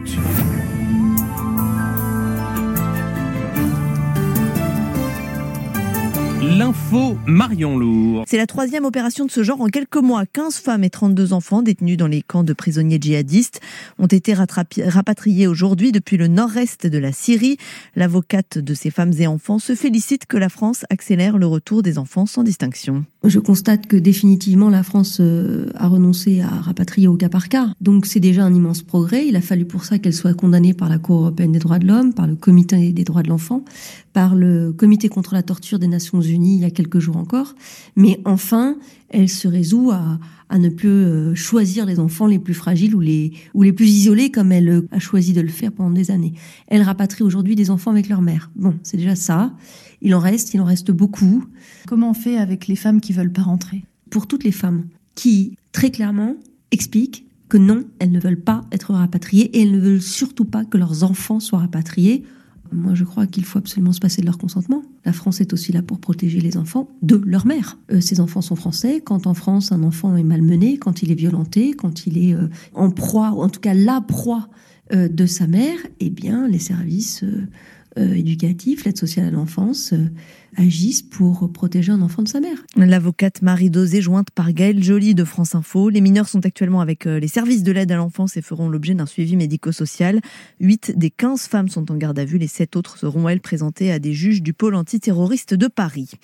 Journal de 9H